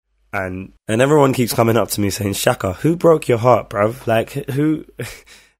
読み方
シャカ
SBTV Live Performanceより本人の読み